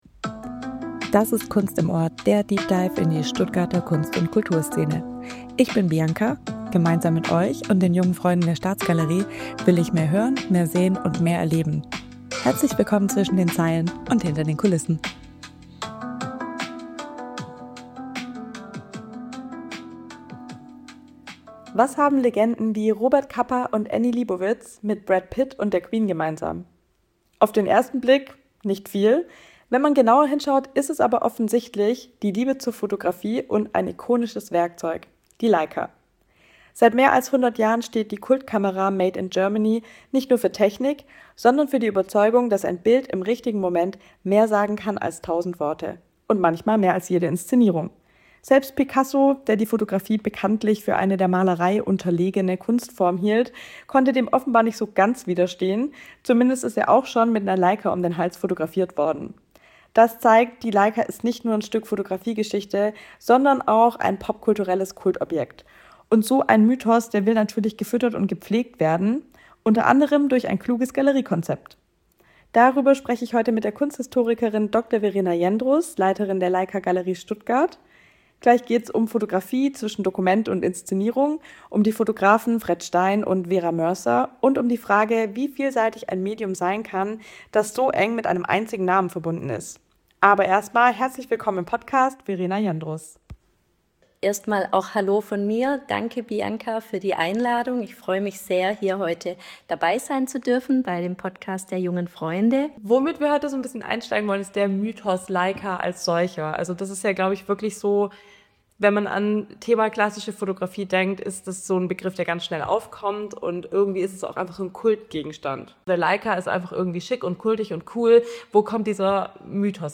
Im Gespräch geht es um die besondere Geschichte der Leica und um die Haltung, die mit ihr verbunden ist.